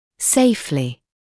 [‘seɪflɪ][‘сэйфли]безопасно; надежно